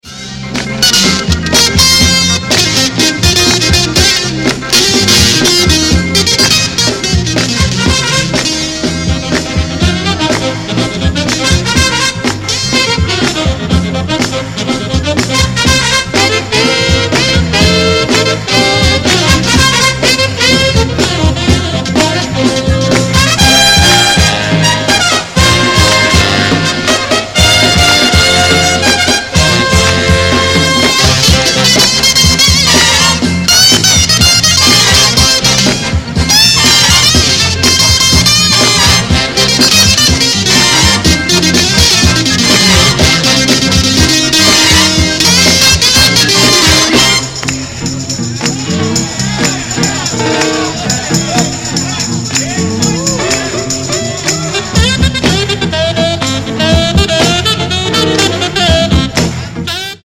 Every track a big-band winner!